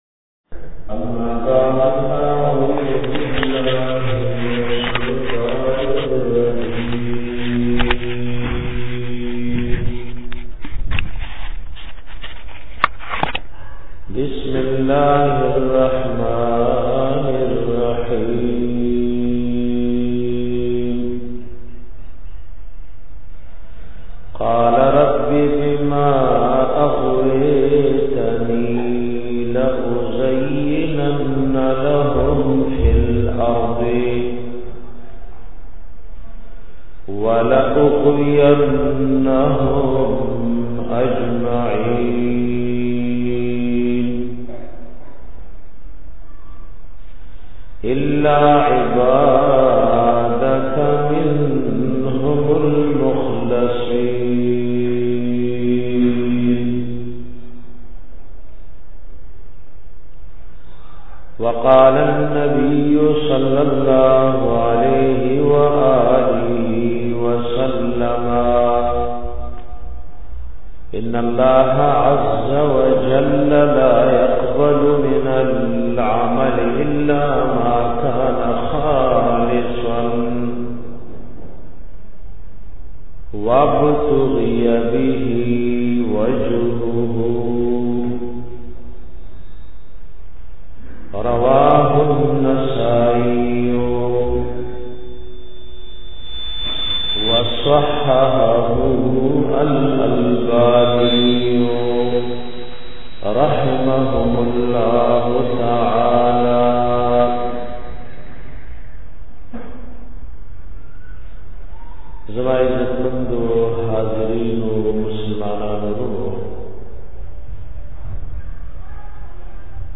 bayan da sheethan da bachao asbabu ka 1